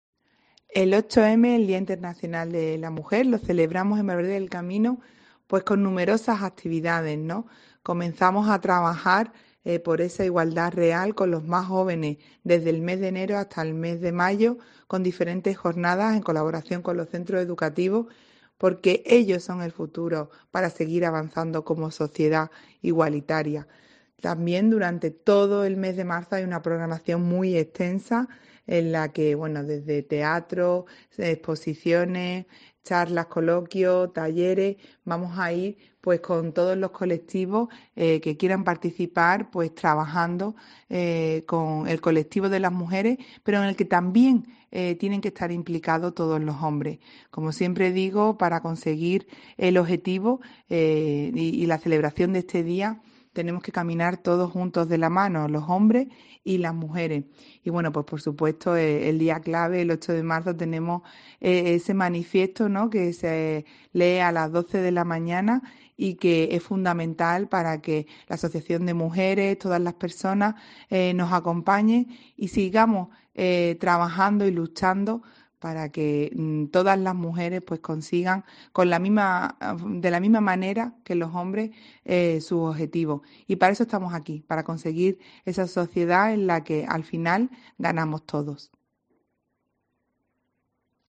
Syra Senra, alcaldesa de Valverde del Camino